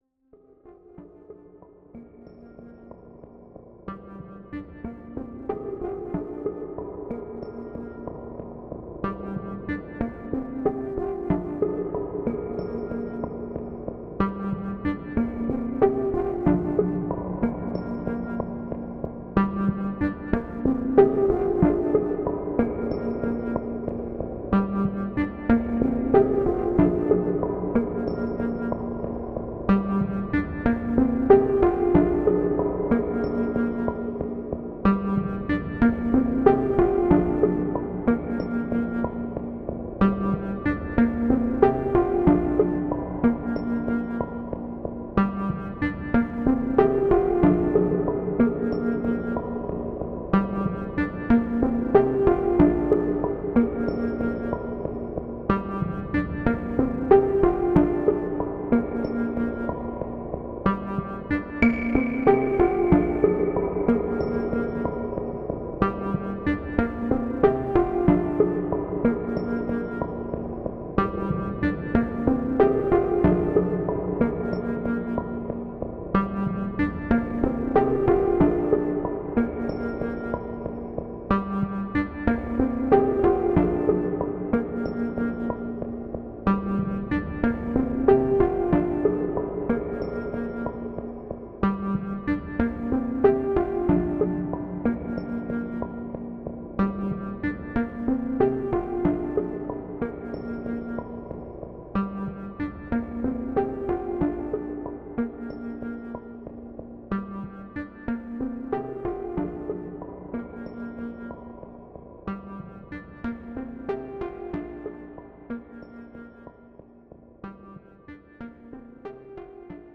- special support for multitimbral V2 synthesizer